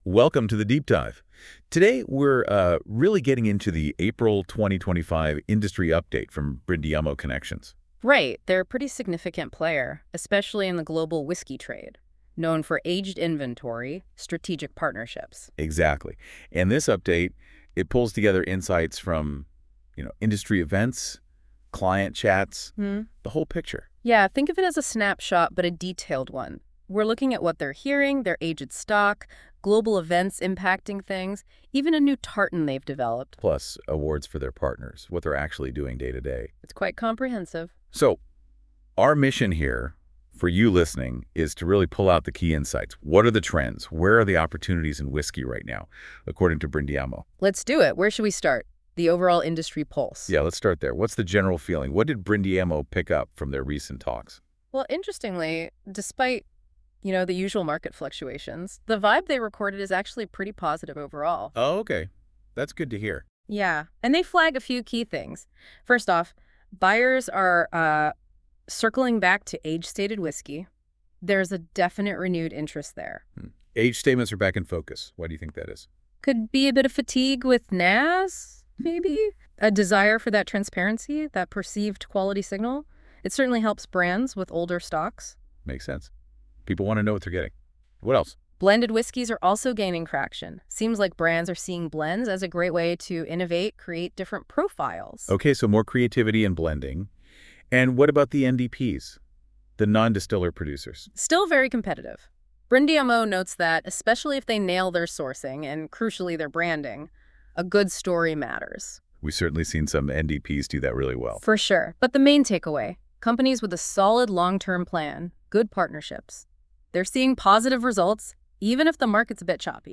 If you prefer to listen to the latest Brindiamo updates captured above, check out our 10 minute digitally-created